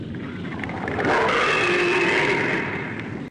File:Gappa Roar Showa.ogg
Gappa_Roar_Showa.ogg